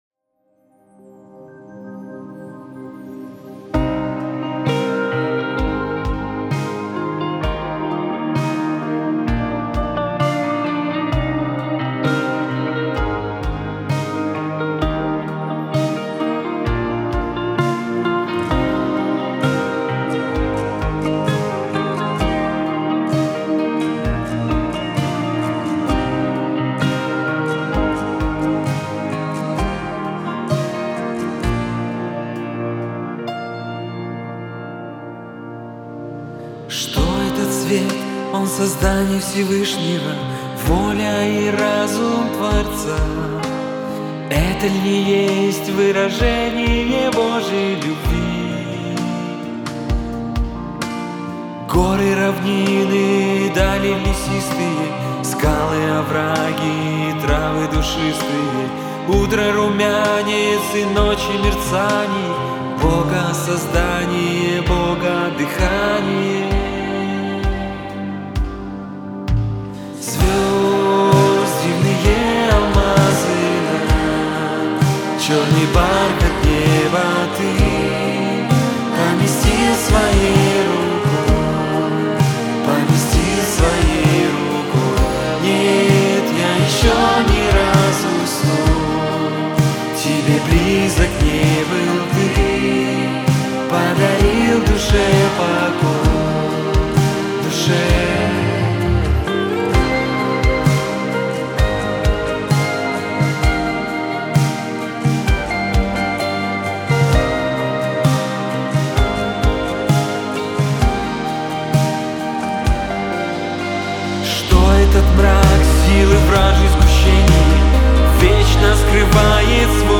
571 просмотр 1163 прослушивания 73 скачивания BPM: 65